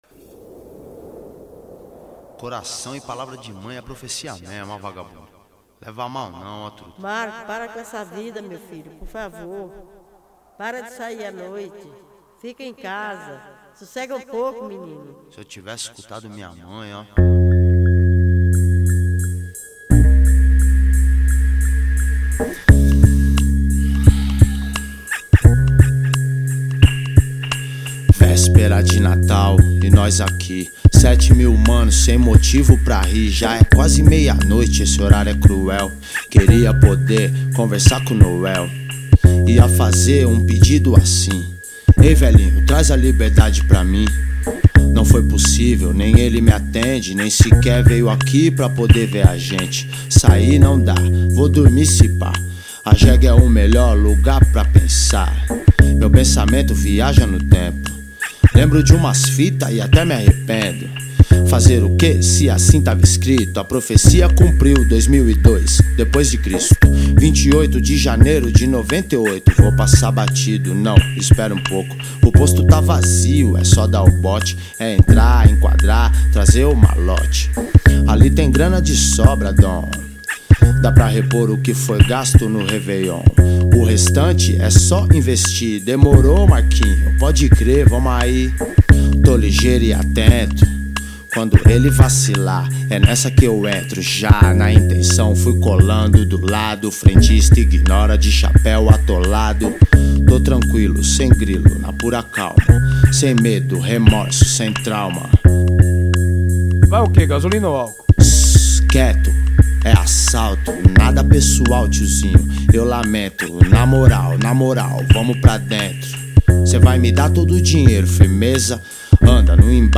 2024-05-10 21:29:20 Gênero: Rap Views